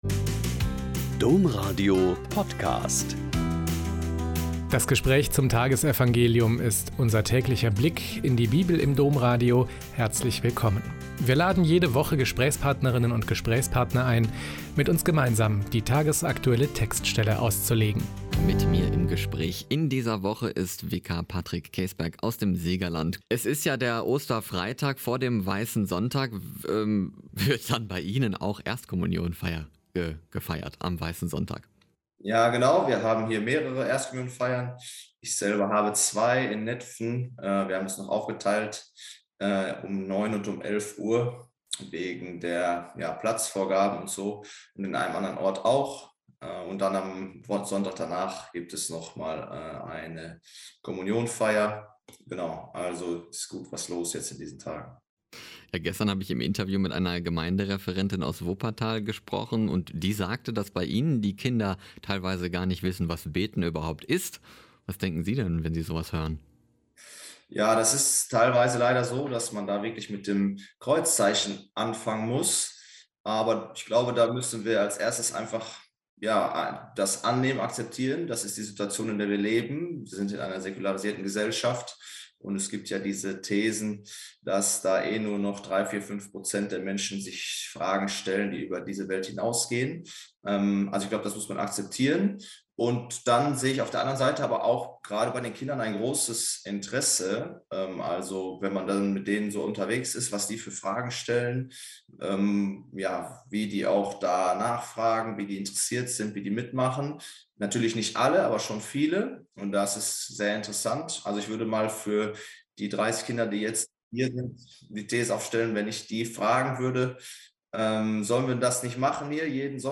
Joh 21,1-14 - Gespräch